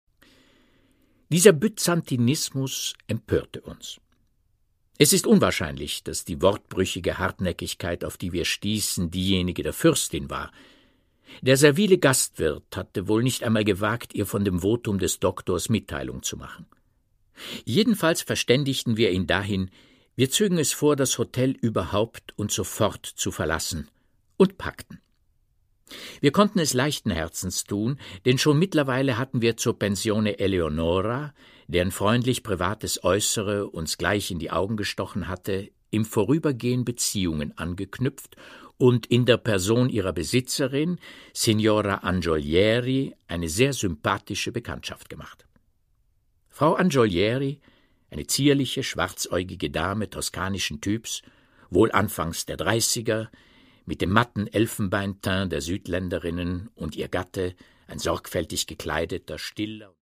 Produkttyp: Hörbuch-Download
Gelesen von: Peter Matić